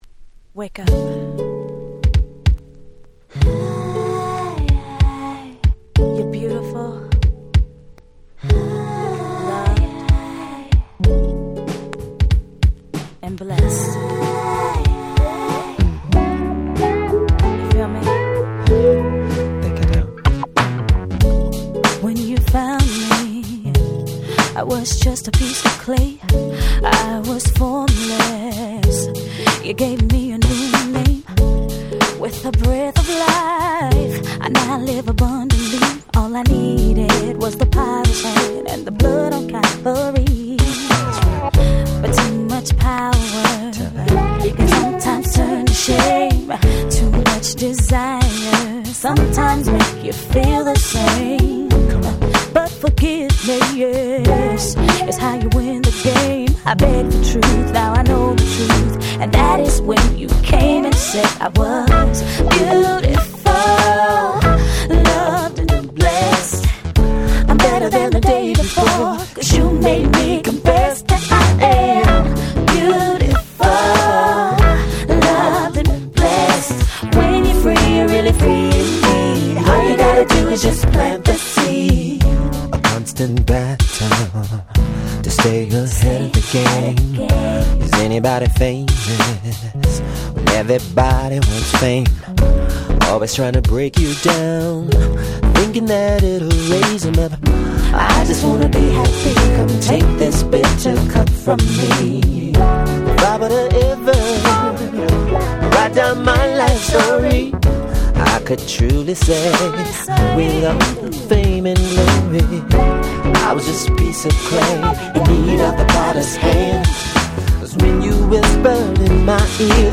06' Super Nice R&B / Neo Soul !!